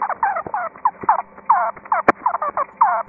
豚鼠 ( 雌性 )
描述：雌性天竺鼠的叫声。雄性天竺鼠用单音节呼叫，但雌性天竺鼠可以用单音节和双音节呼叫。
声道立体声